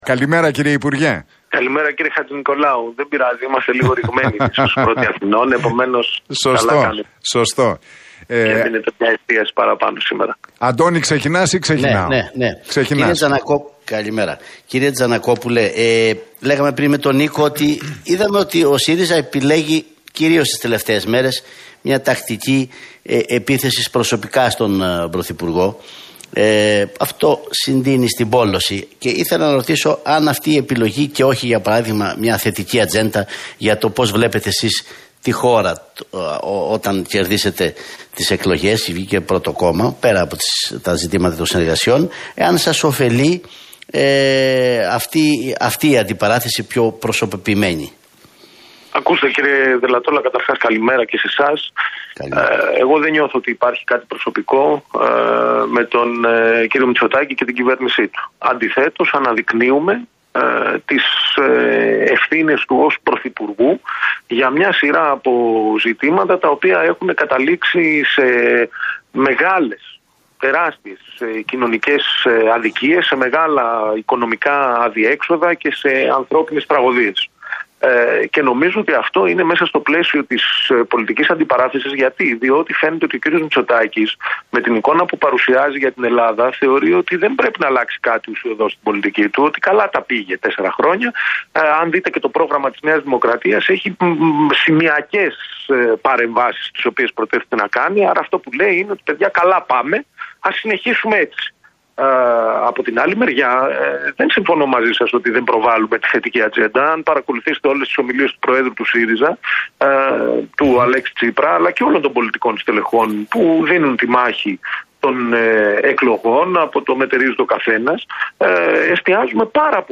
Ο υποψήφιος με τον ΣΥΡΙΖΑ, Δημήτρης Τζανακόπουλος, μίλησε στον Νίκο Χατζηνικολάου